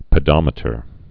(pĭ-dŏmĭ-tər)